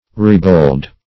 ribauld - definition of ribauld - synonyms, pronunciation, spelling from Free Dictionary Search Result for " ribauld" : The Collaborative International Dictionary of English v.0.48: Ribauld \Rib"auld\, n. A ribald.